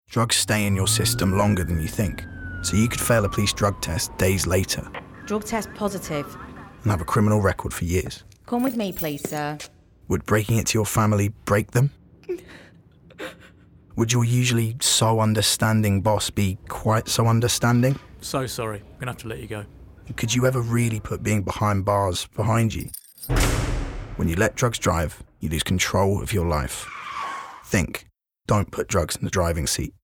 30s Advert